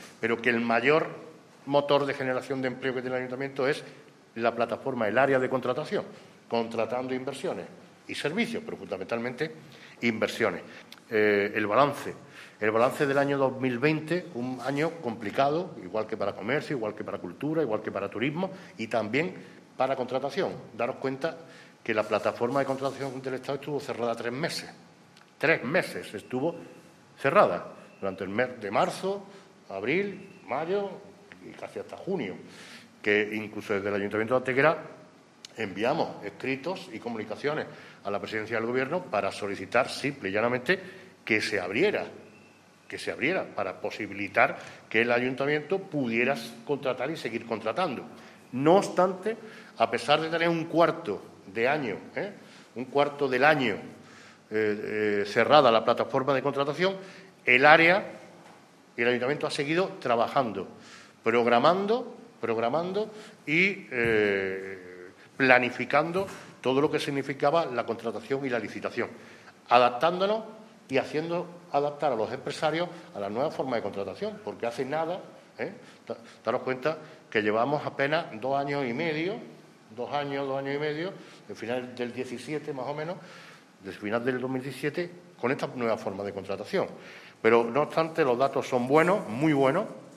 ENLACE A VÍDEO DE LA RUEDA DE PRENSA EN YOUTUBE
Cortes de voz